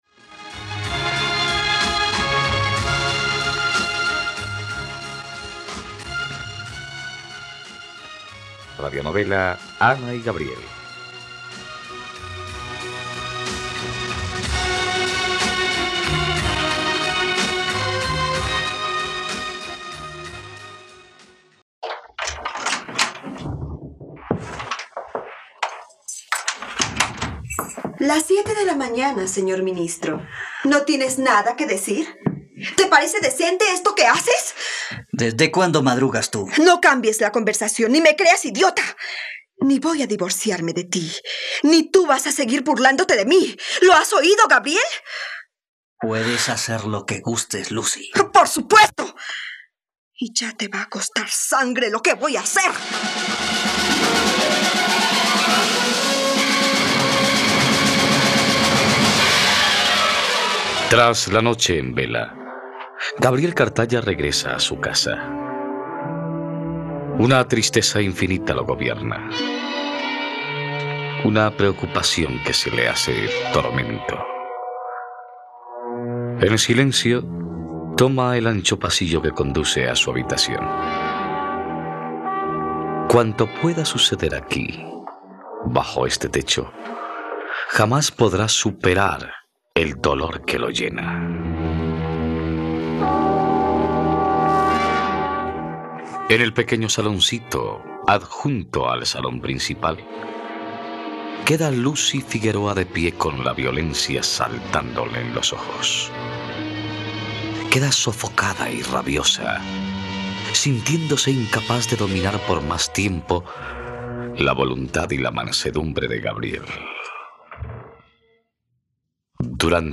..Radionovela. Escucha ahora el capítulo 87 de la historia de amor de Ana y Gabriel en la plataforma de streaming de los colombianos: RTVCPlay.